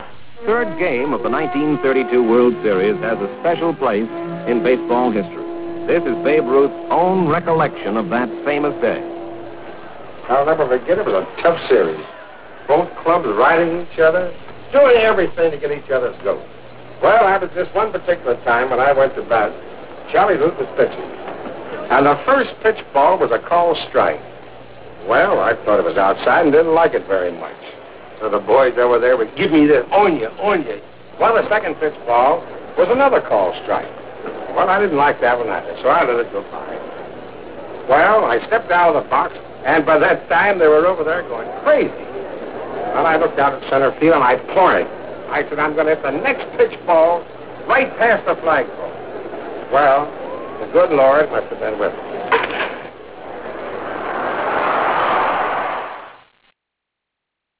Comments on the called shot in RealAudio by The Babe.